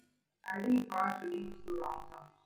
描述：噪声信号的去噪信号SNR为10dB
Tag: lab4 瀑布 维纳